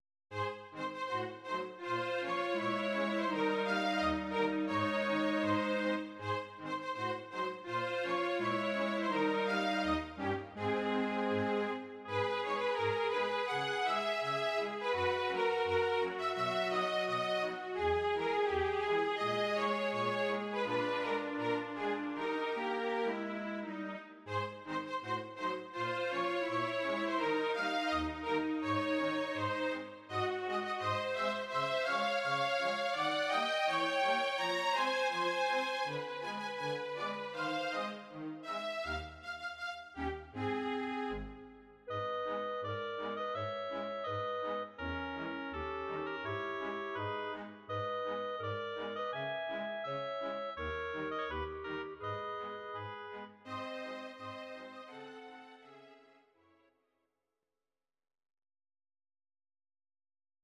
Audio Recordings based on Midi-files
Musical/Film/TV, Ital/French/Span, 1960s